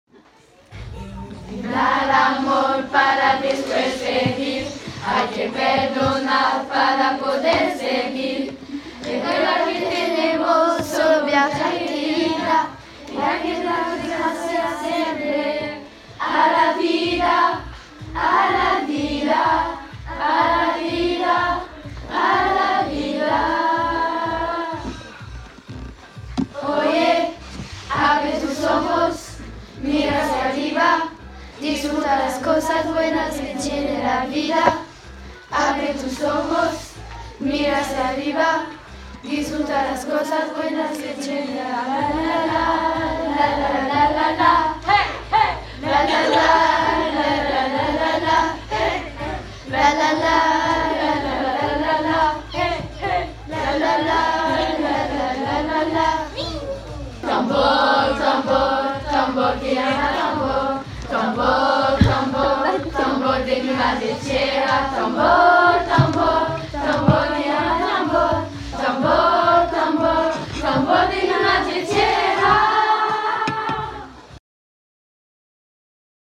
Voici une petite chanson, interprétée par les élèves de 6ème bilangue, pour célébrer la vie.